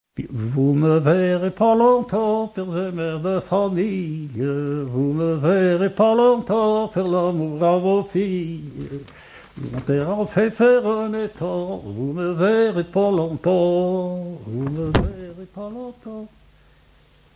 Chant de conscrits
Pièce musicale inédite